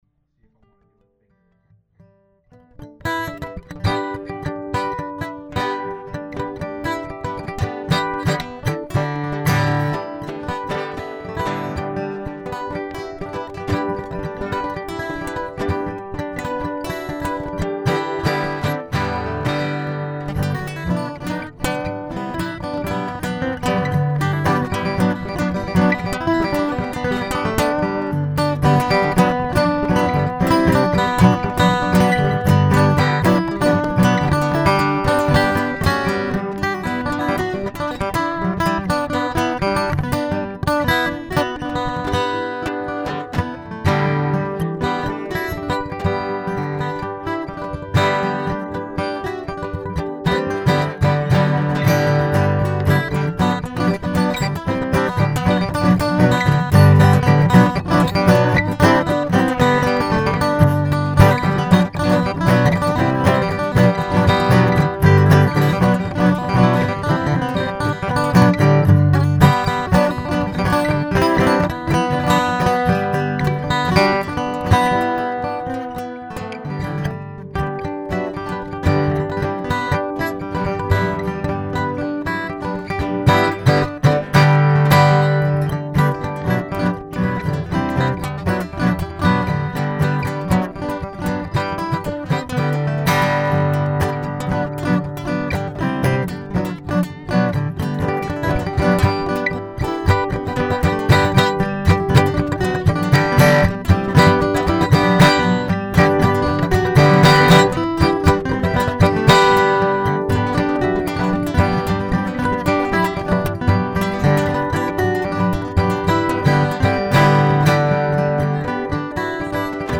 Jamming 2019-04-06
Fiddle, Guitar
acoustic , jam